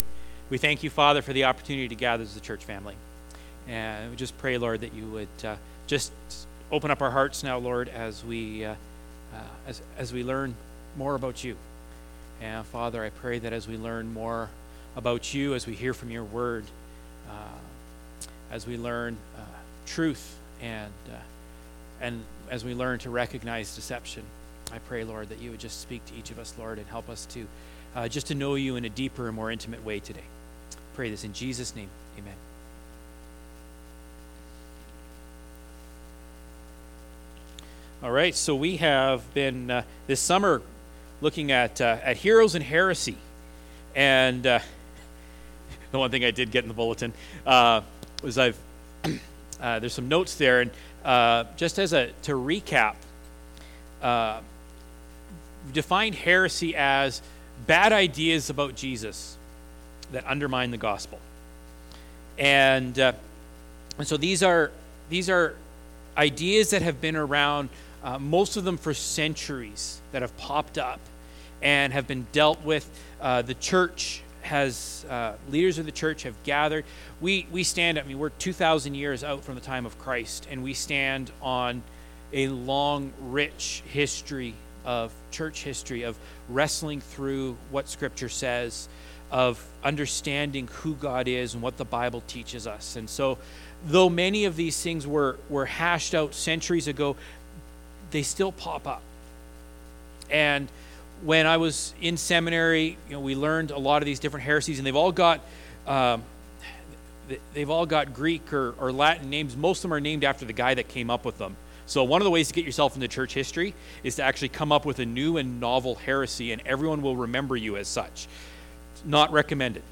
Sermons | Central Fellowship Baptist Church